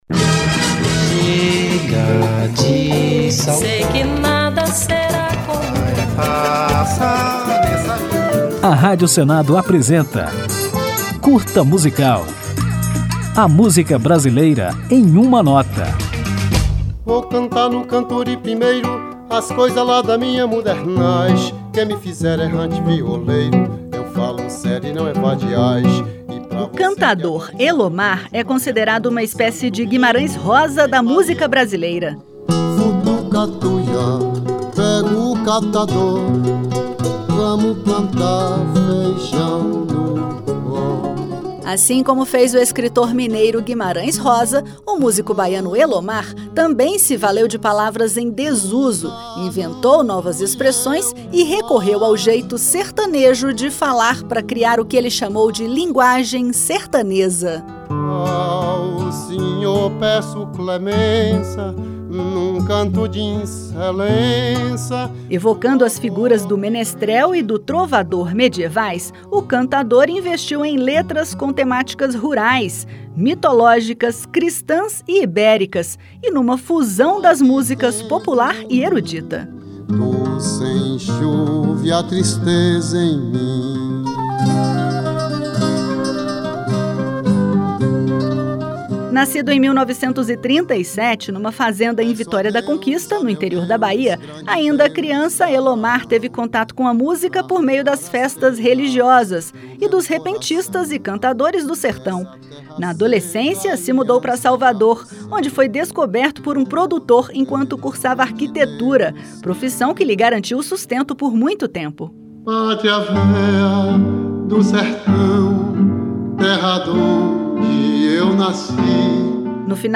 Depois apresentada a importância e a qualidade de Elomar, vamos ouvi-lo na música O Violeiro, lançada em 1972.